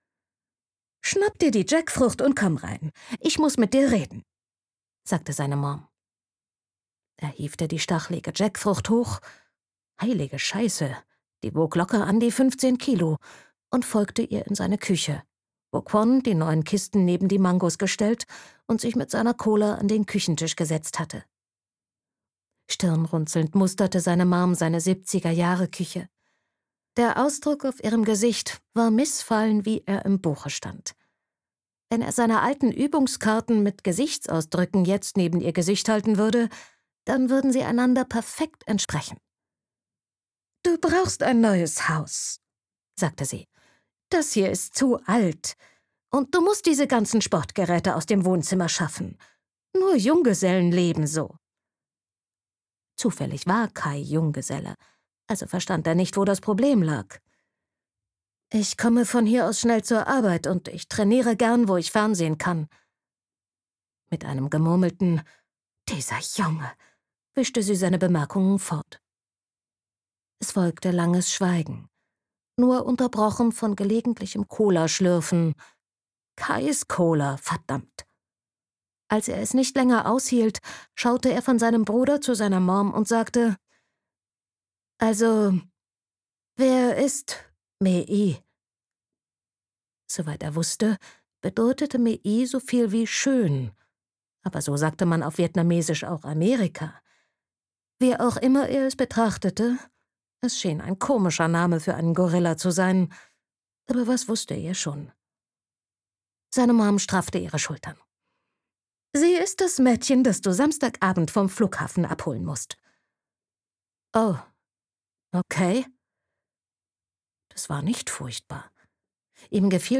Autorisierte Lesefassung Argon